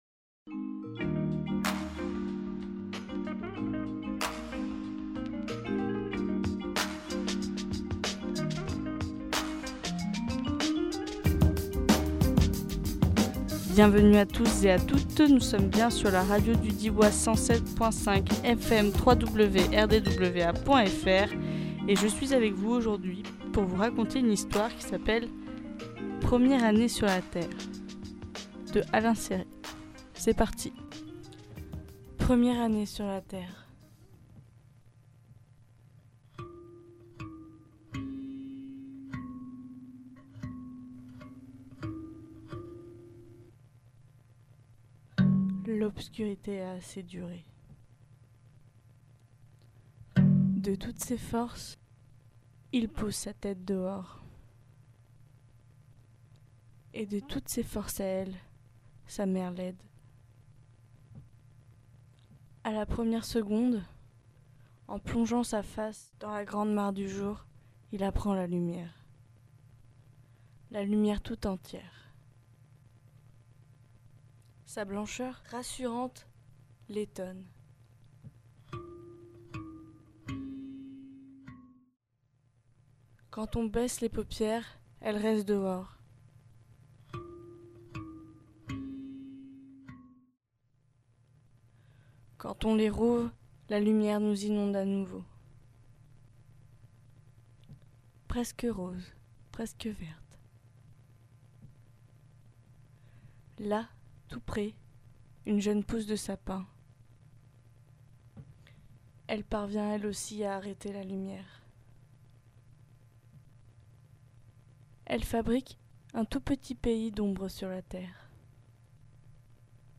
Lieu : Studio RDWA